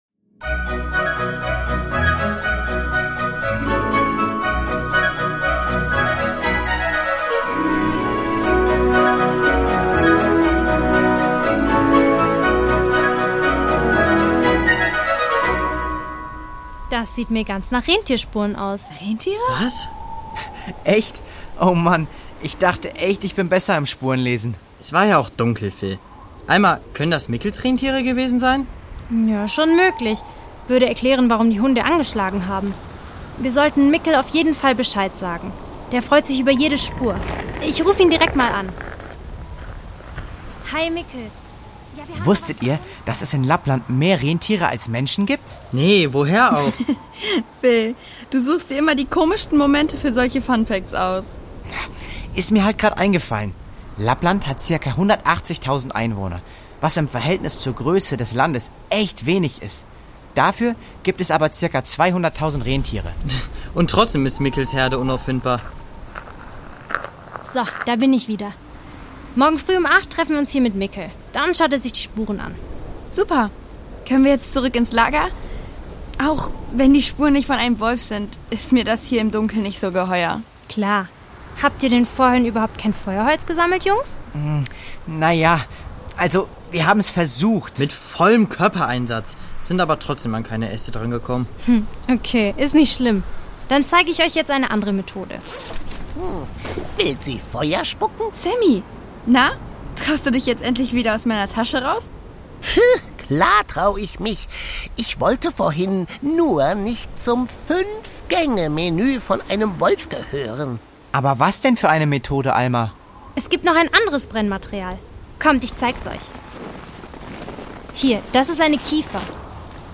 Lappland: Ein Rätsel im Schnee (7/24) | Die Doppeldecker Crew | Hörspiel für Kinder (Hörbuch)